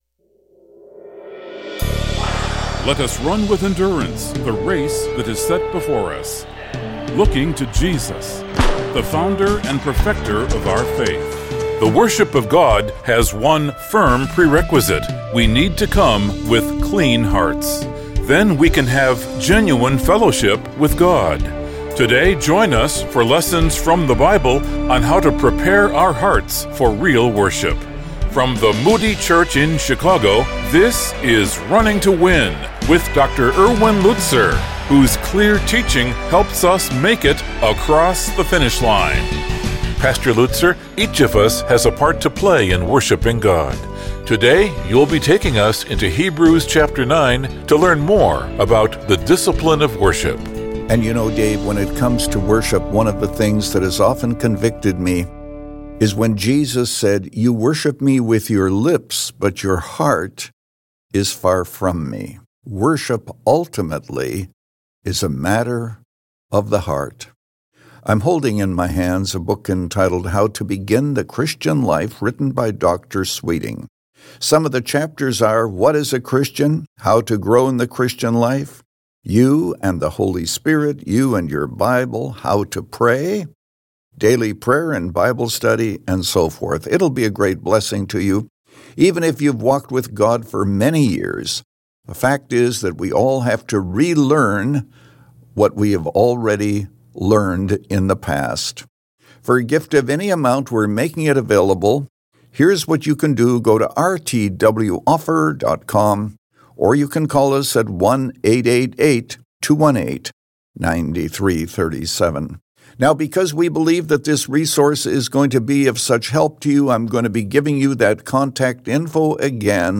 But the worship of God has one firm prerequisite: We need to come with clean hearts. In this message